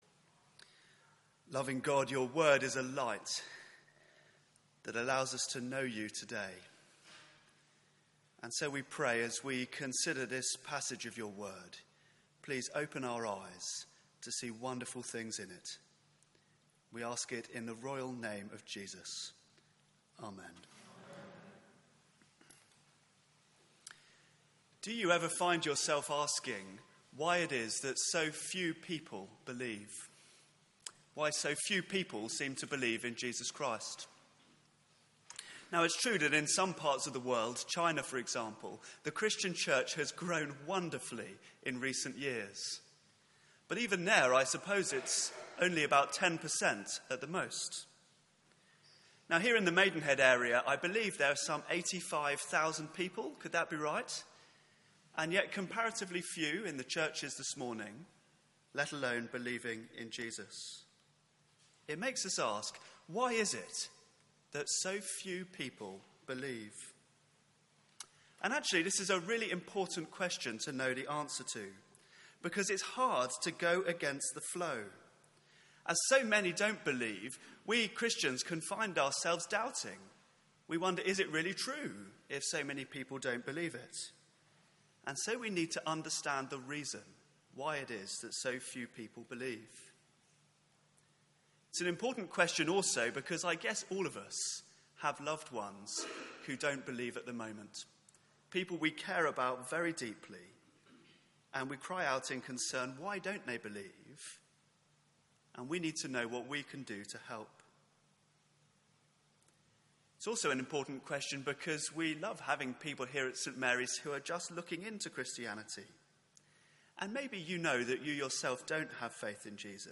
Media for 9:15am Service on Sun 08th May 2016
Theme: None so blind Sermon